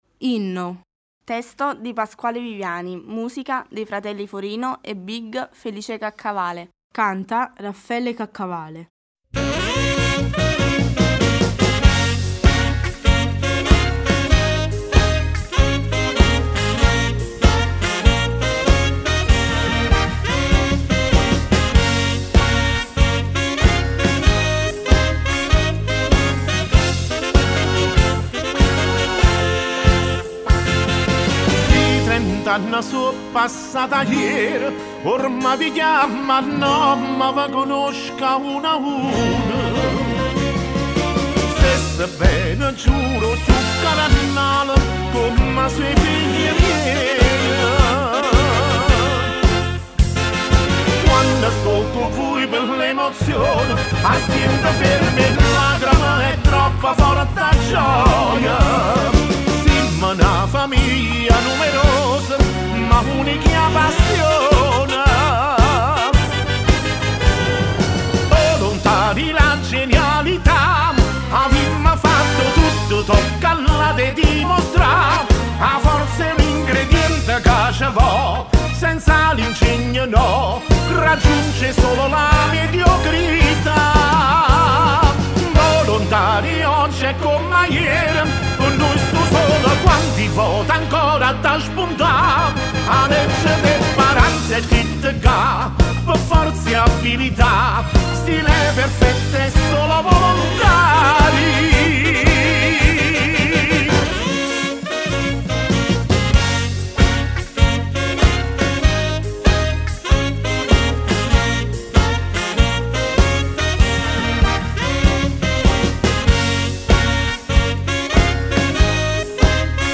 Cantanti